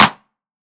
Asap Clap1.wav